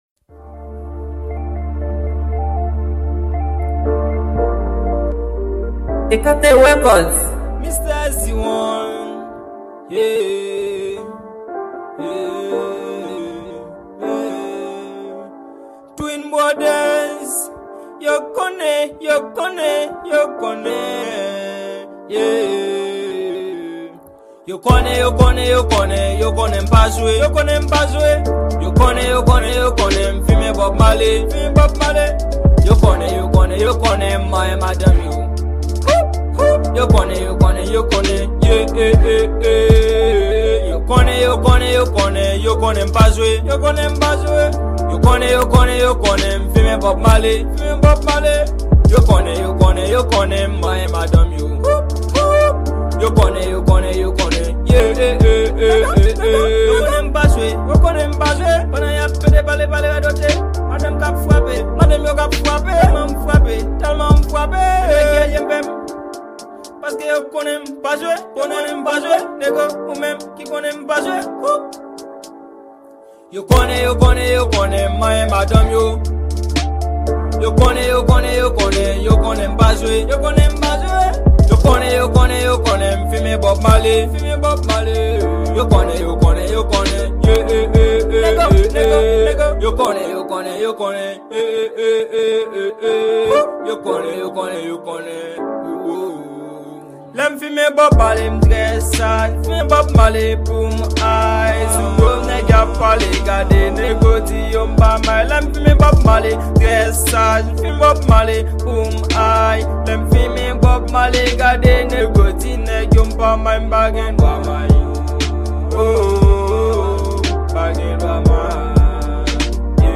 Genre : Trap